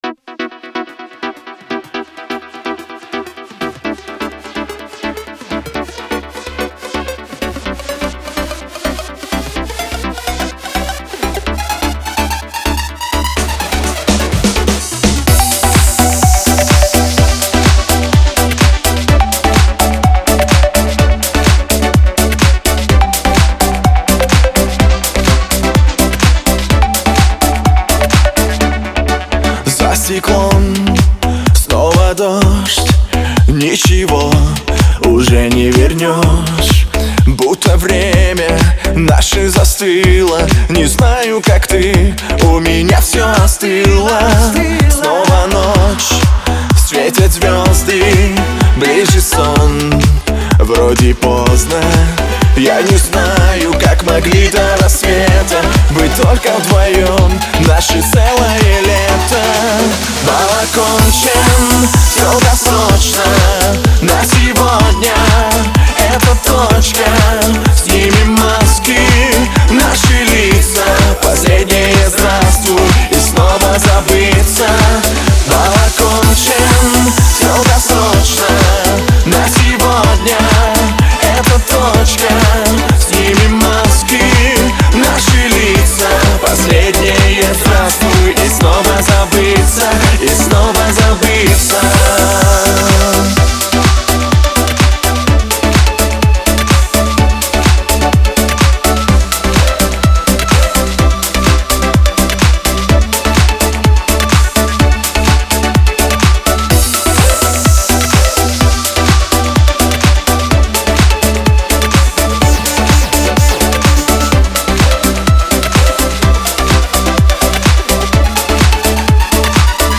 это трогательная песня в жанре поп-музыки